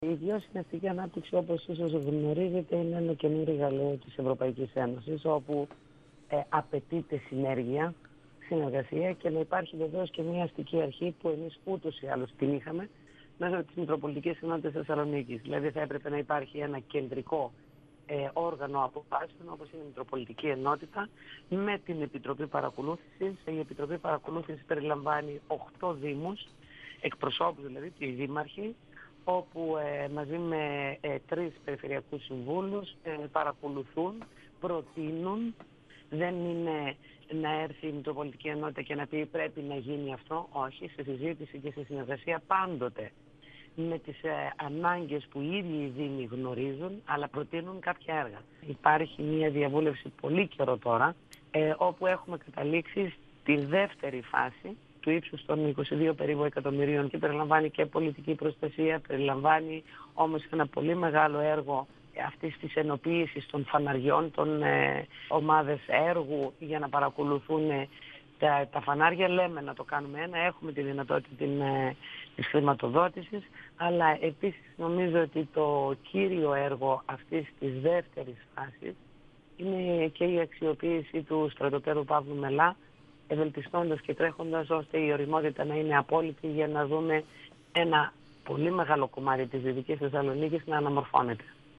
H αντιπεριφερειάρχης Θεσσαλονίκης, Βούλα Πατουλίδου, στον 102FM του Ρ.Σ.Μ. της ΕΡΤ3
Συνέντευξη